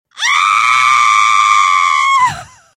Хотите скачать без SMS короткий mp3 фрагмент "Крик женщины"?
Живые звуки, имитация